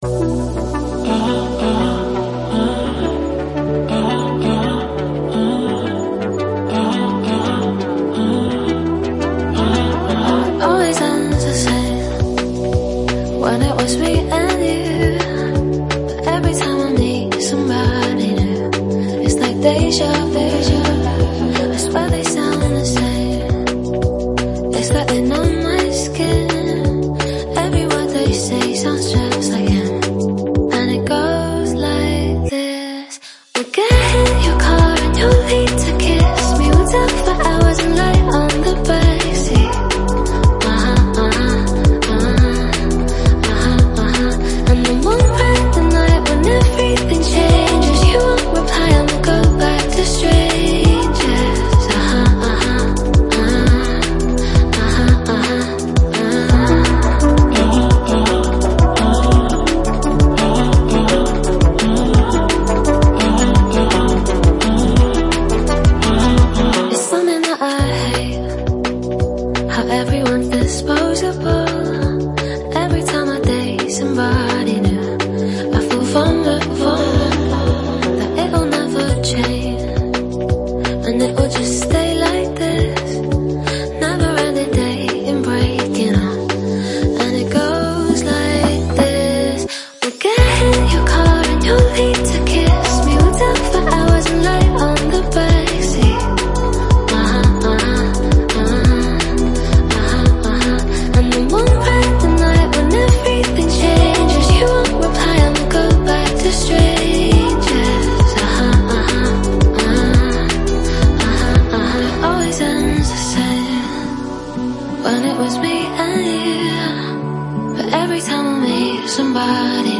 драм-н-бэйс песню